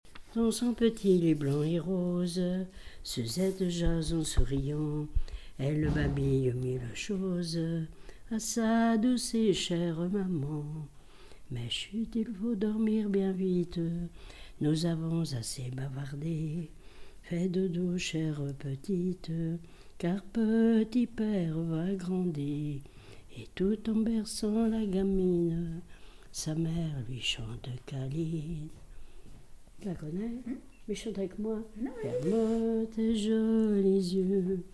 témoignage et chansons
Pièce musicale inédite